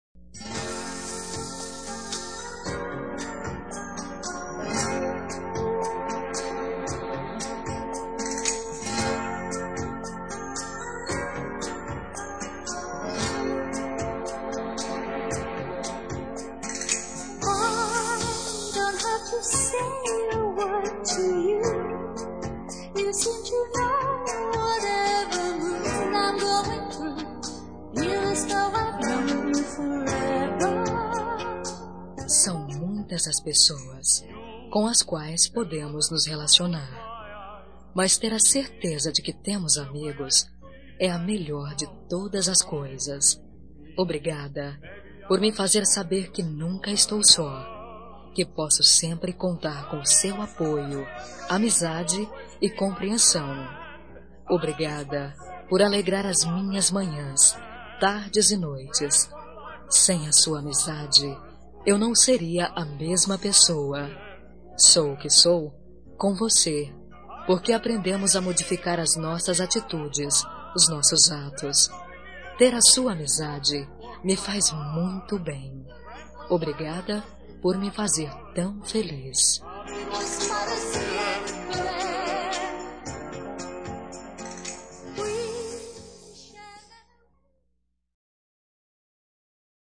Telemensagem de Aniversário de Amigo – Voz Feminina – Cód: 1560